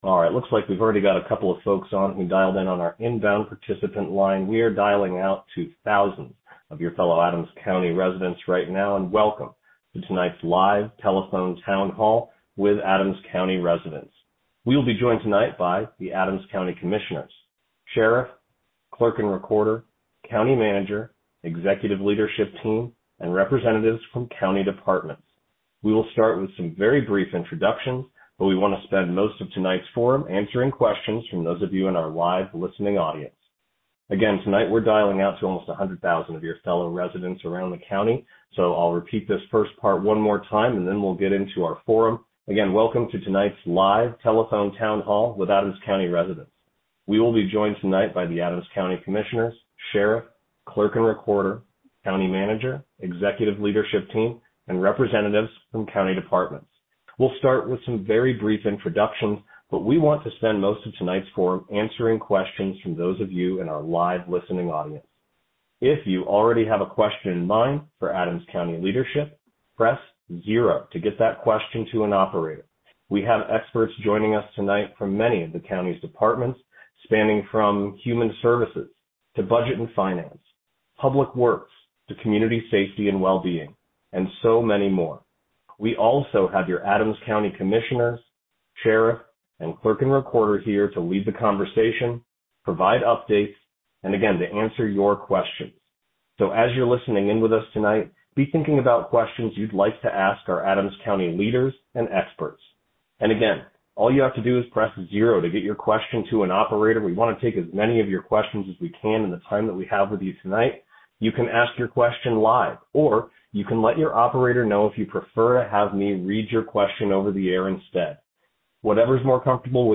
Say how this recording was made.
Join a live Telephone Town Hall with your Adams County, Colorado Commissioners to talk through funding, federal issues, social programs, community concerns, and more—all from the convenience of your phone.